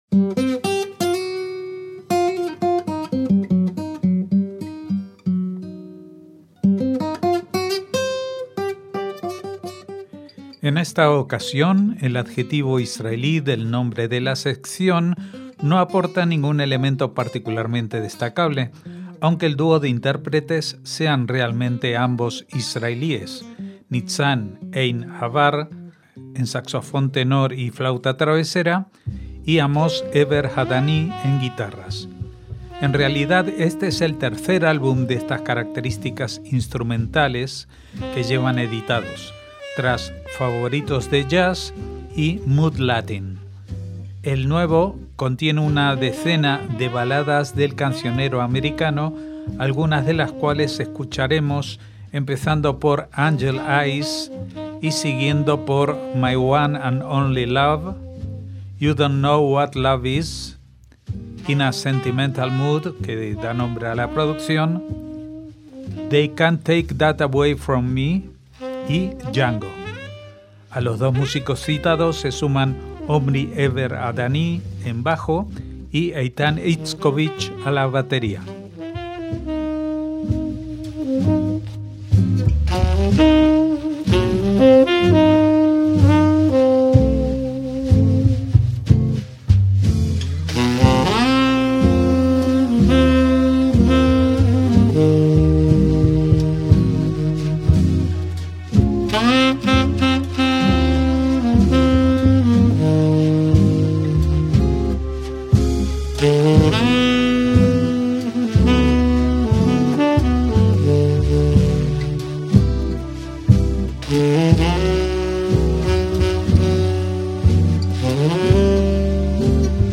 saxofón tenor y flauta travesera
guitarras
una decena de baladas del cancionero americano
bajo